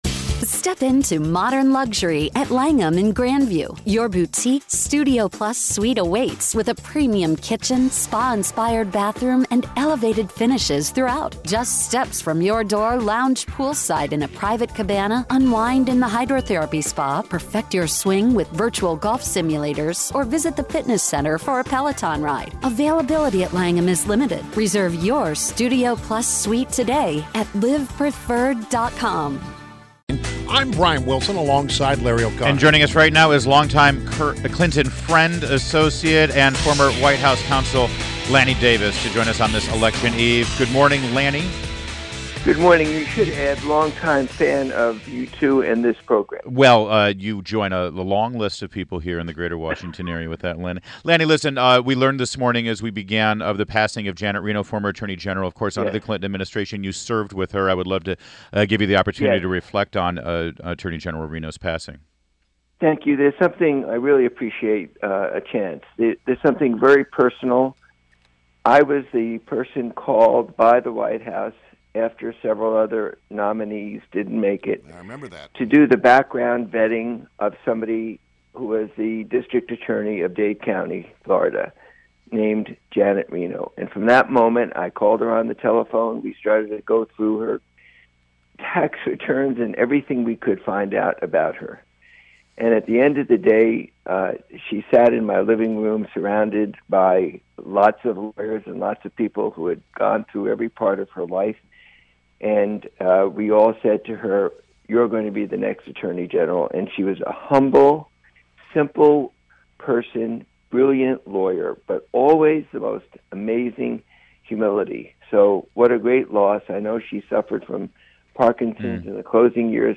WMAL Interview - LANNY DAVIS - 11.07.16
INTERVIEW — LANNY DAVIS – former special counsel to former President Bill Clinton and columnist for The Hill